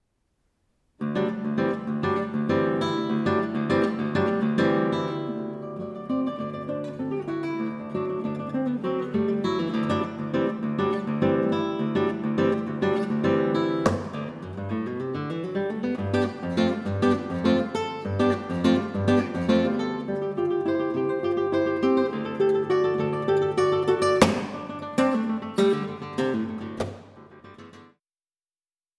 für Gitarre
guitar